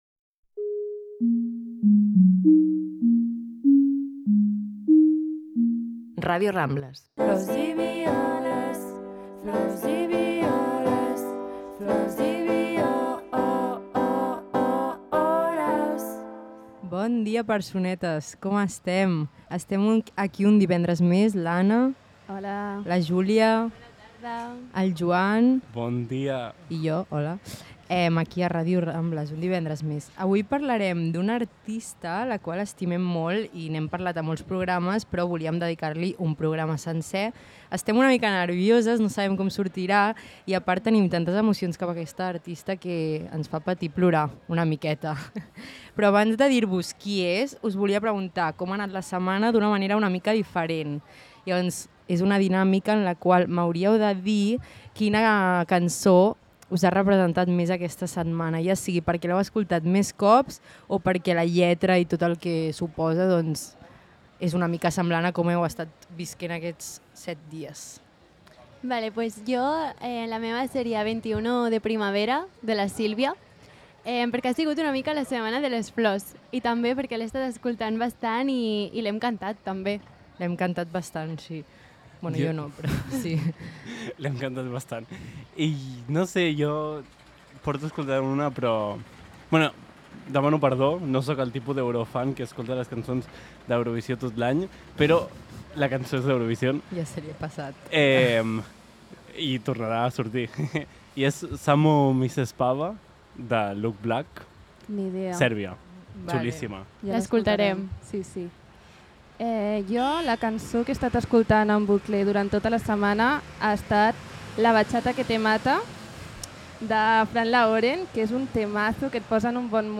Una conversa de bar entre quatre amigues que xerren sobre música però que tampoc tenen gaire idea de res i que tot ho fan per la curiositat de temes que potser no han estat tant al punt de mira.